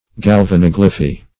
Search Result for " galvanoglyphy" : The Collaborative International Dictionary of English v.0.48: Galvanoglyphy \Gal`va*nog"ly*phy\, n. [Galvanic + Gr.